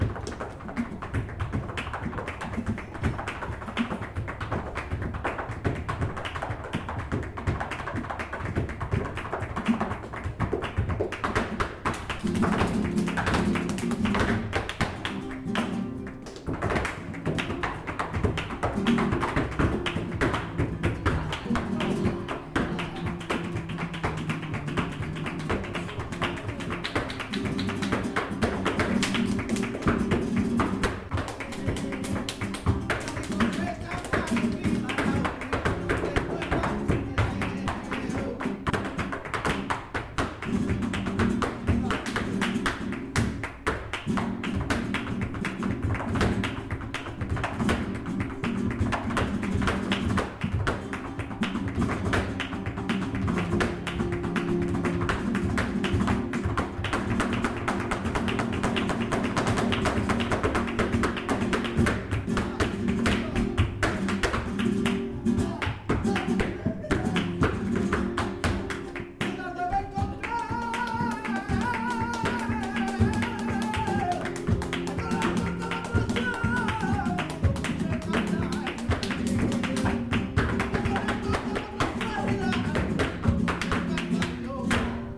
wild reimagined sounds of flamenco in Seville.